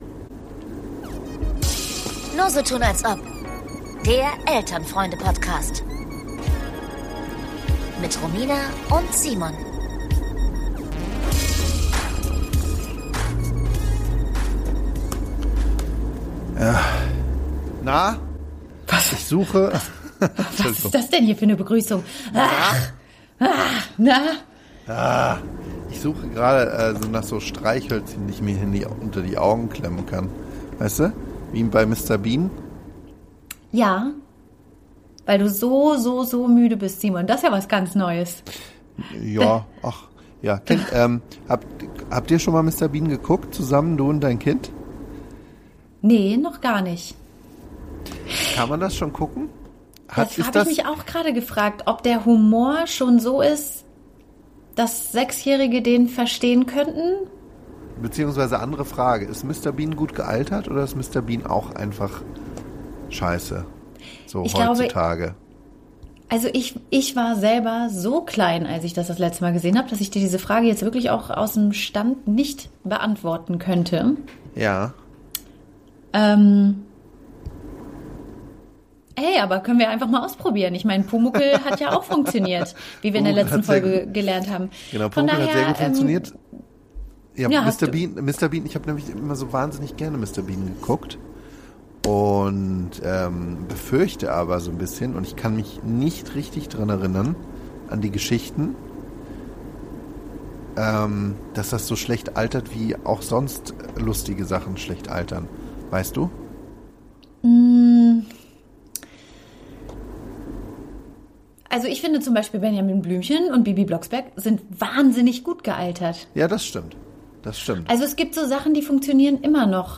Gerade warm gequasselt lässt die beiden dann doch das Internet im Stich. Oder ein Kabel.